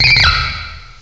cry_not_scraggy.aif